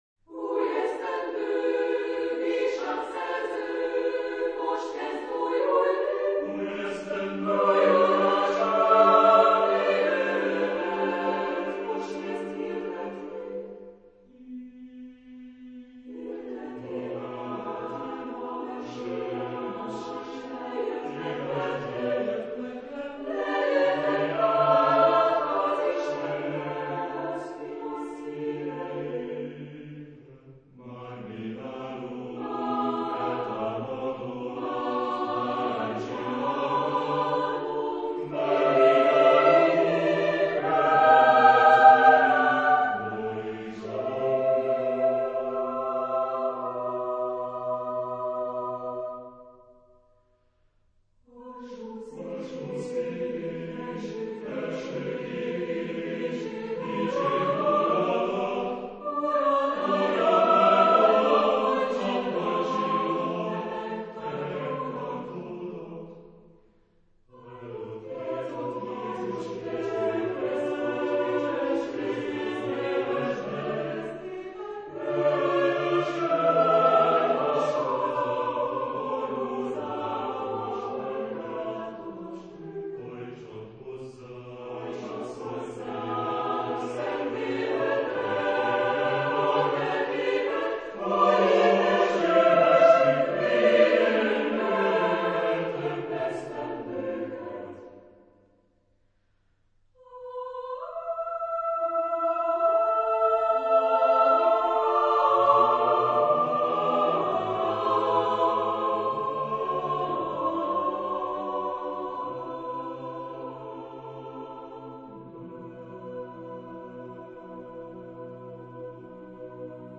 Epoque: 20th century  (1900-1949)
Genre-Style-Form: Secular ; Popular ; Choir
Type of Choir: SSA  (3 children OR women voices )